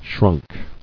[shrunk]